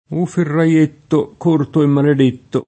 ferraio [ferr#Lo] s. m. (ant. «febbraio») — forma sopravvissuta solo in qualche proverbio: O ferraietto, corto e maledetto! [
o ferraL%tto, k1rto e mmaled%tto!] (Pascoli); O ferraiuzzo, piccolino e putto! [o ferraL2ZZo, pikkol&no e pp2tto!] (id.) — cfr. febbraio